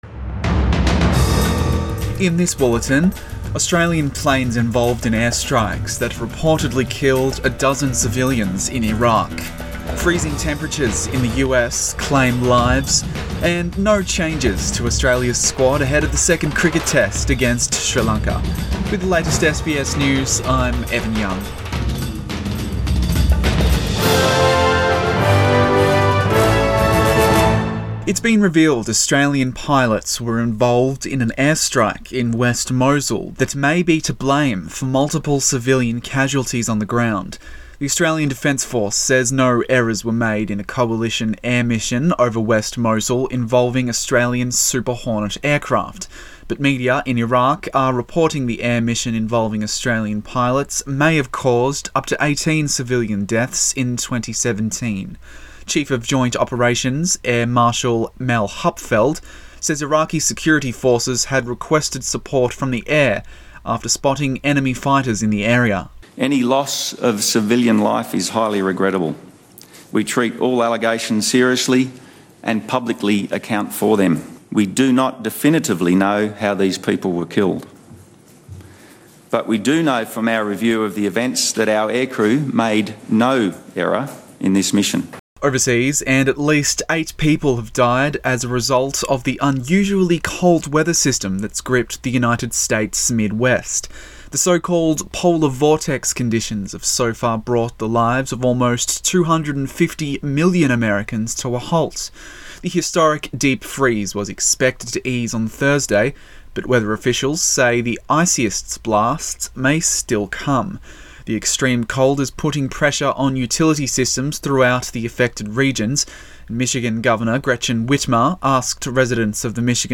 AM bulletin 1 February